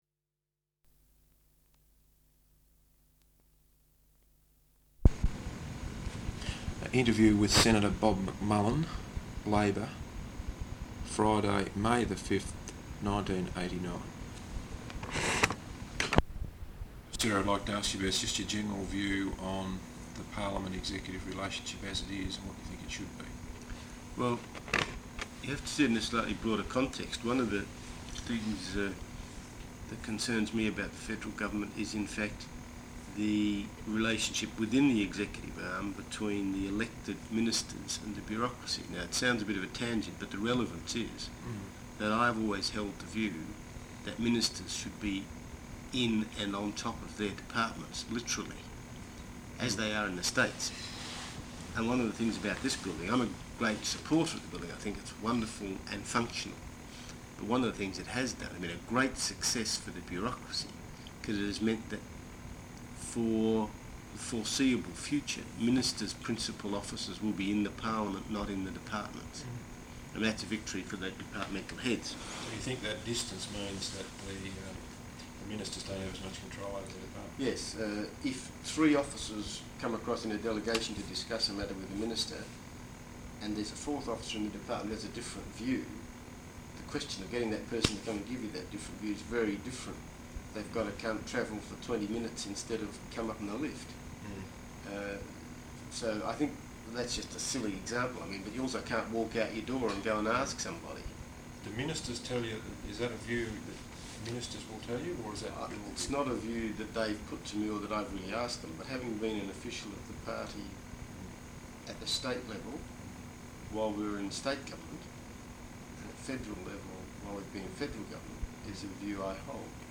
Interview with Senator Bob McMullan, Labor, Friday May 5th 1989.